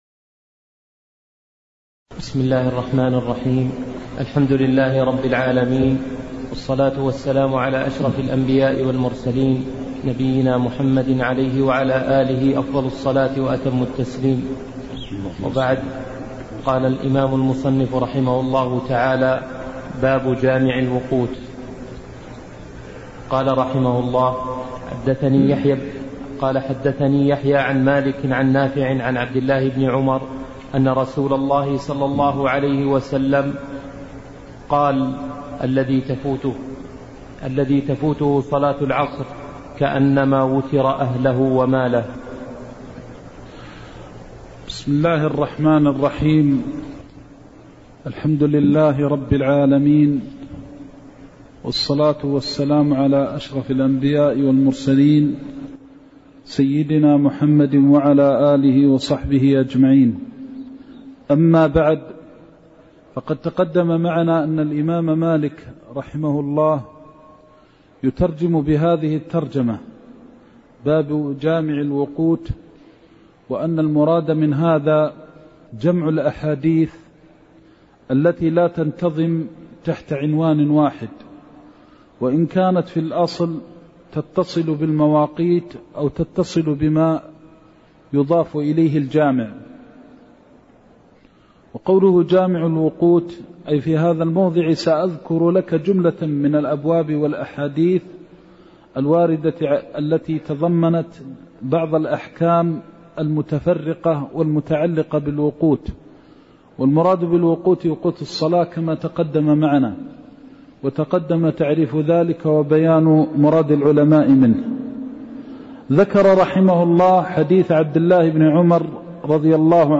الدرس الخامس من قول المصنف رحمه الله :باب جامع الوقوت إلى قول المصنف رحمه الله :باب النهي عن دخول المسجد بريح الثوم وتغطية الفم